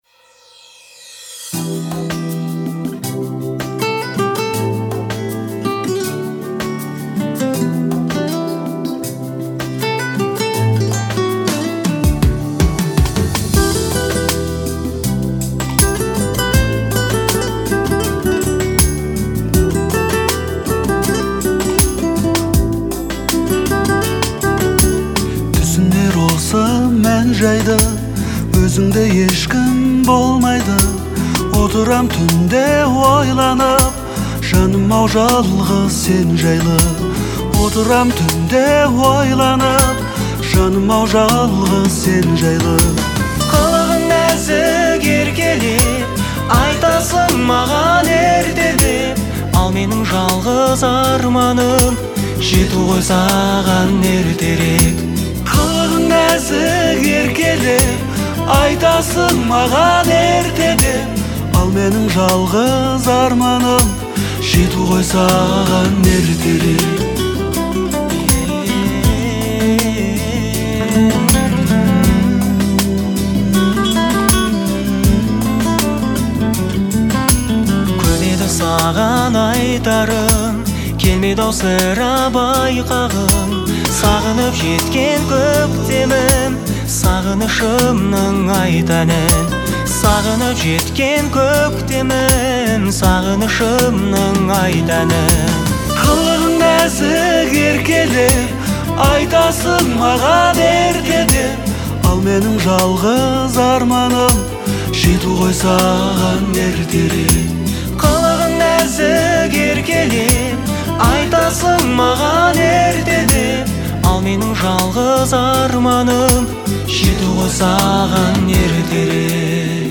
это романтическая песня в жанре казахского попа